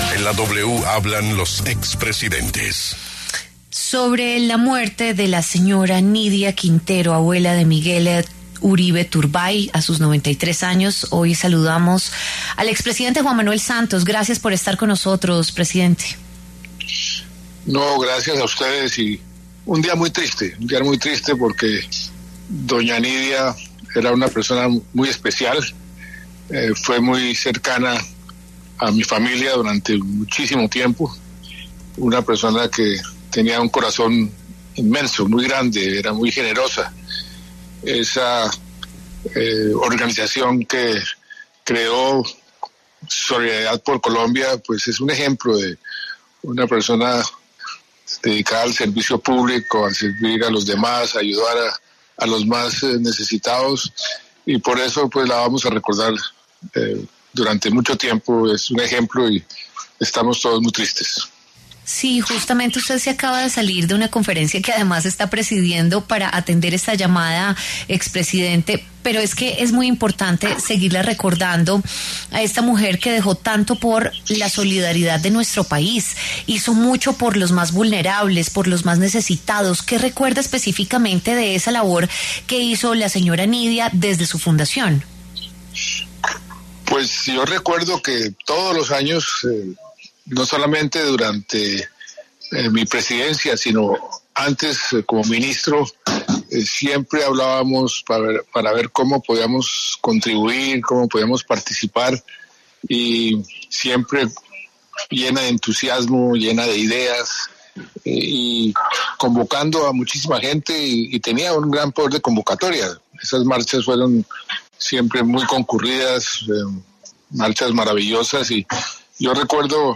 El expresidente de la República Juan Manuel Santos pasó por los micrófonos de La W para expresar sus condolencias y compartir anécdotas en su memoria.